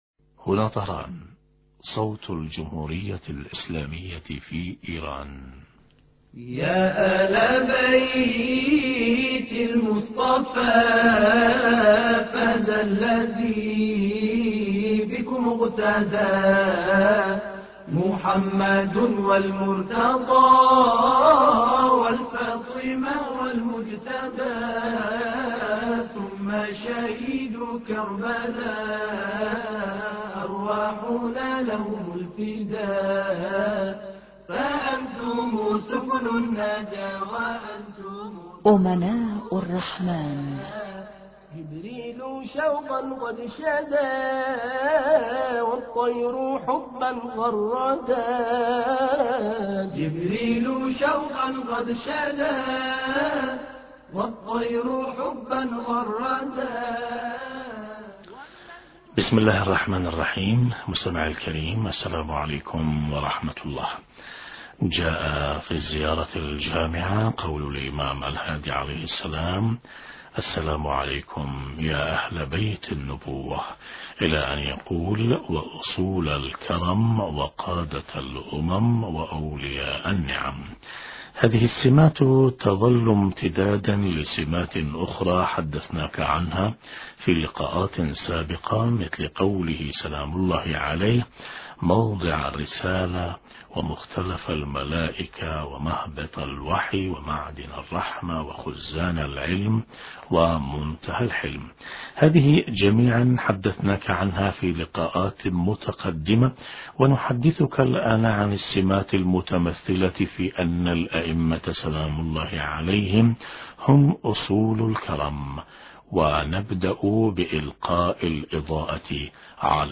هذا الاتصال الهاتفي والحوار